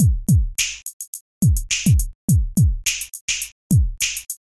描述：Stirring Baseballs Thump bump movement
标签： Thump Stirring Baseballs bump